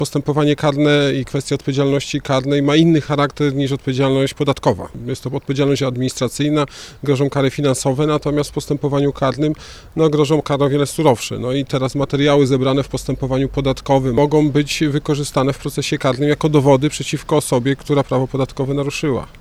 Swoją wiedzą dzielił się również prof. Dariusz Świecki, sędzia Sądu Najwyższego.